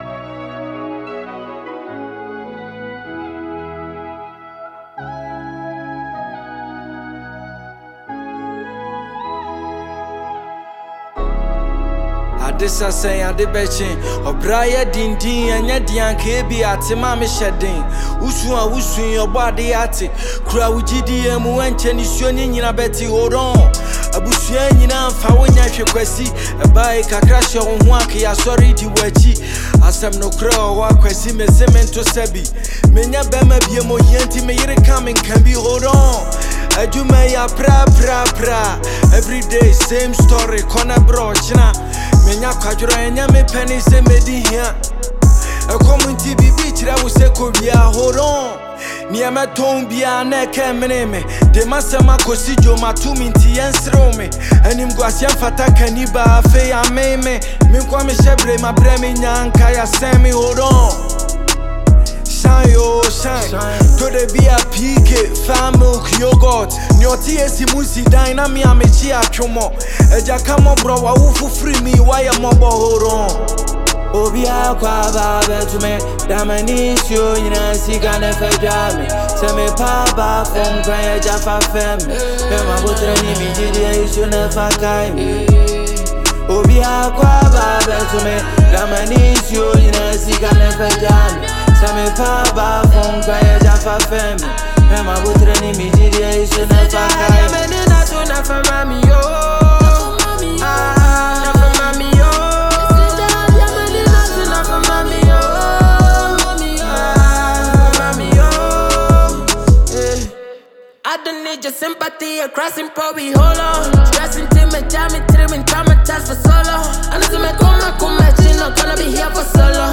New one from Ghanaian rapper